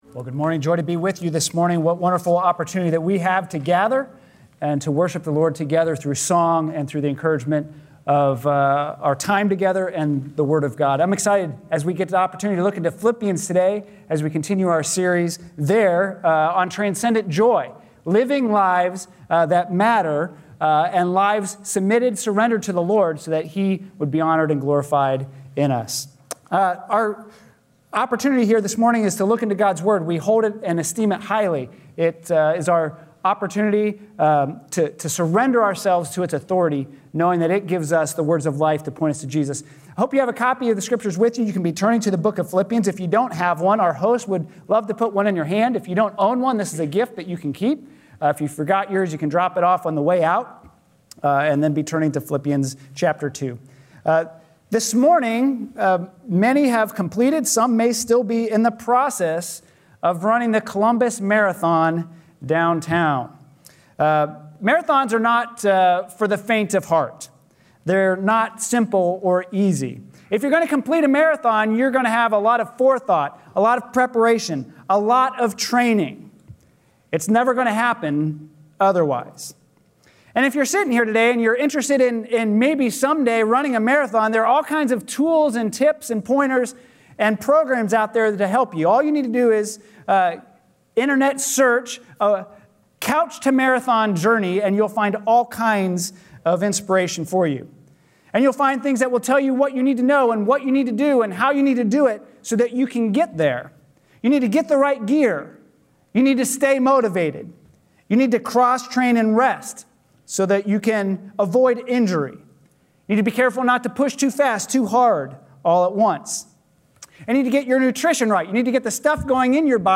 A sermon from the series "Transparent."